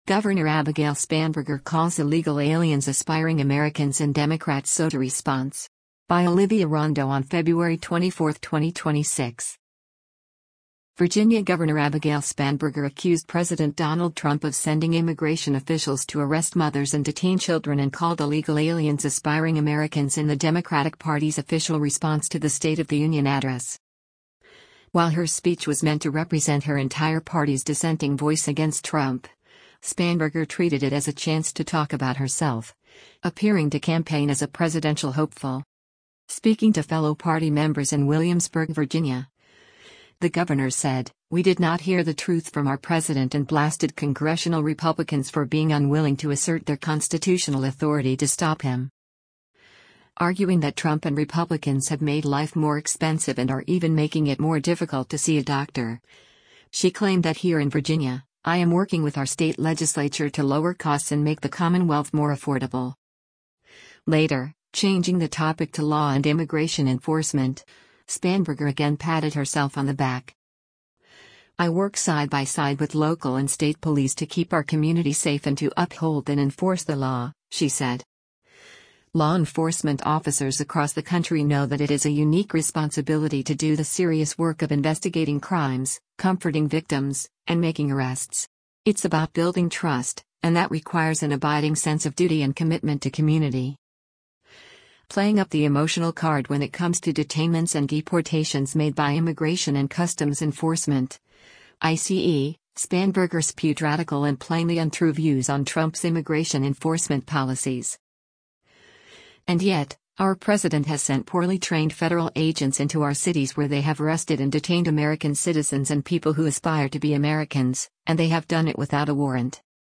Virginia Gov. Abigail Spanberger delivers the Democratic response to President Donald Trum
Speaking to fellow party members in Williamsburg, Virginia, the governor said, “We did not hear the truth from our president” and blasted Congressional Republicans for being “unwilling to assert their constitutional authority to stop him.”